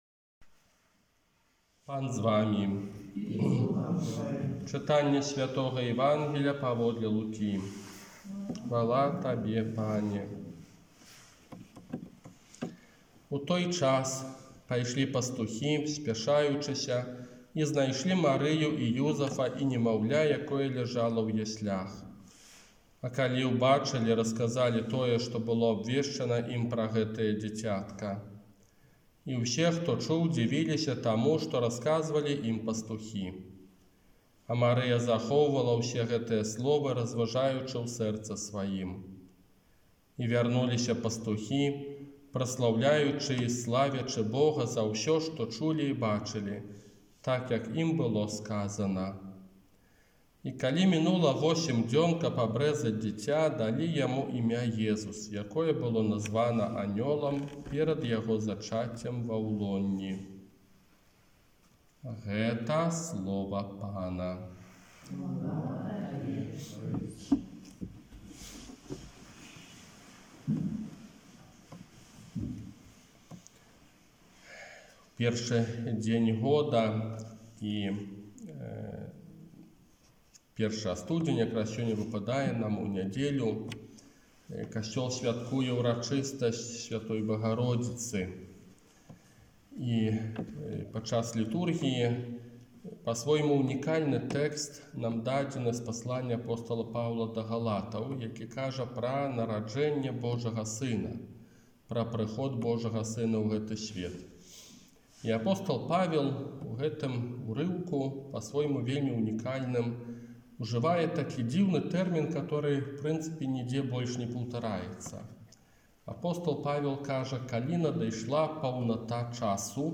ОРША - ПАРАФІЯ СВЯТОГА ЯЗЭПА
Казанне на ўрачыстасць святой Багародзіцы Марыі